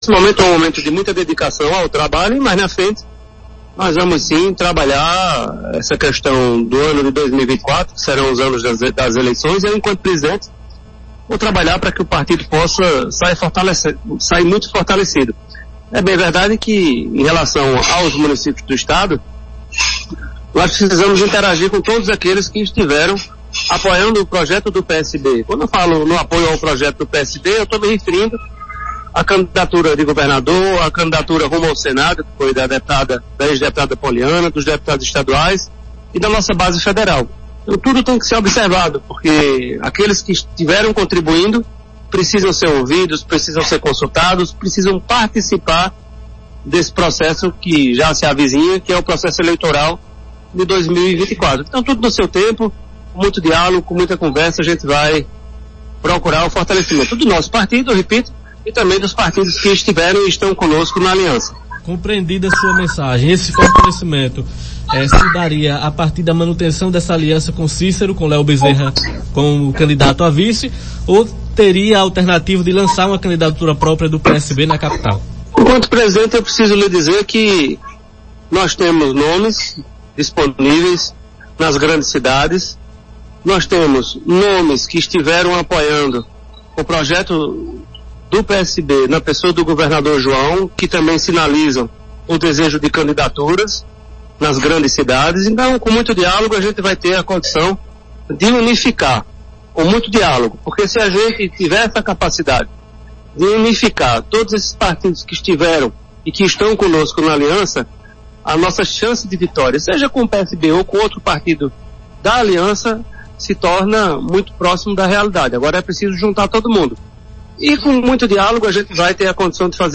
As declarações repercutiram em entrevista ao programa Arapuan Verdade na tarde desta terça-feira (07).